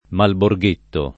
Malborghetto [ malbor g% tto ]